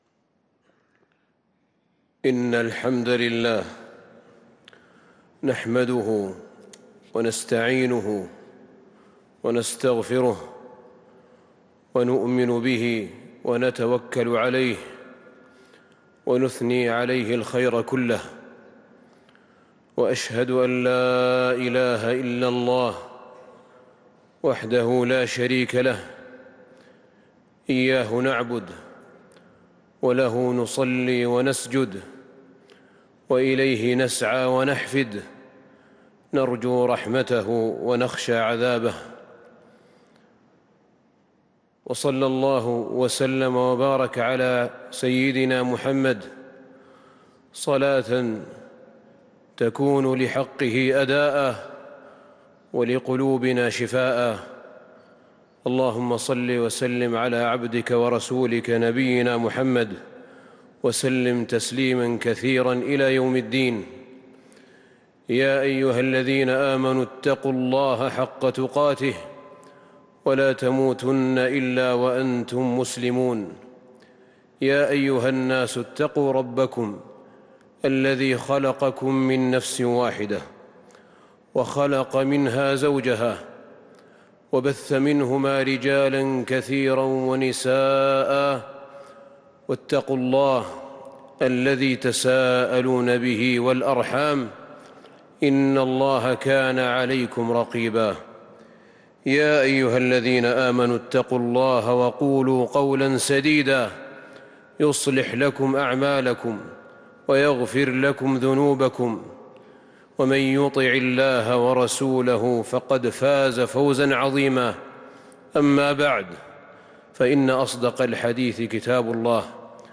خطبة الجمعة ٥ شوال ١٤٤٣هـ | Khutbah Jumu’ah 6-5-2022 > خطب الحرم النبوي عام 1443 🕌 > خطب الحرم النبوي 🕌 > المزيد - تلاوات الحرمين